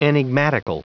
Prononciation du mot enigmatical en anglais (fichier audio)
Prononciation du mot : enigmatical